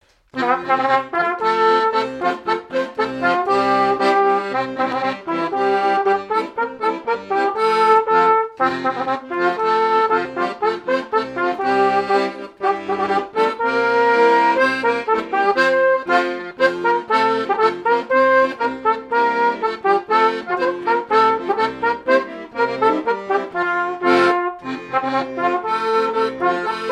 danse : ronde : boulangère
airs de danses issus de groupes folkloriques locaux
Pièce musicale inédite